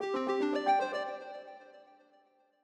Longhorn Ten Beta - Notify Calendar.wav